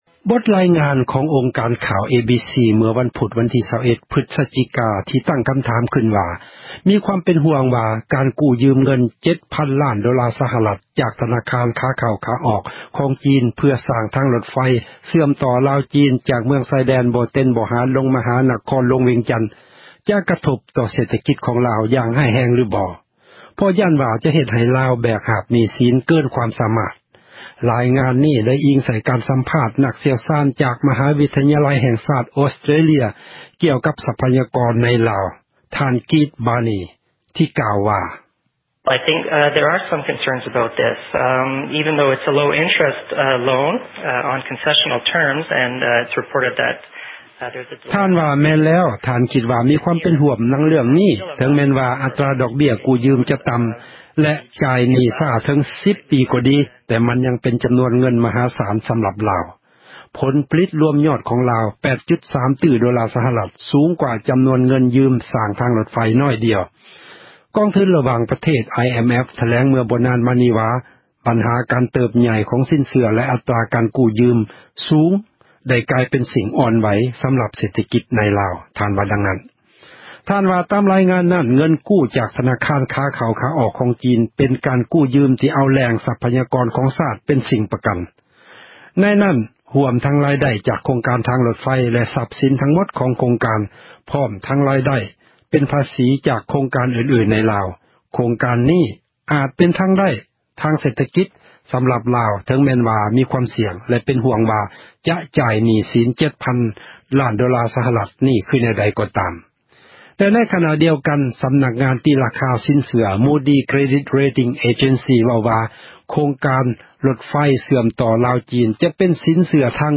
ການຢືມເງິນສ້າງທາງຣົດໄຟ – ຂ່າວລາວ ວິທຍຸເອເຊັຽເສຣີ ພາສາລາວ